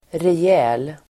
Uttal: [rej'ä:l]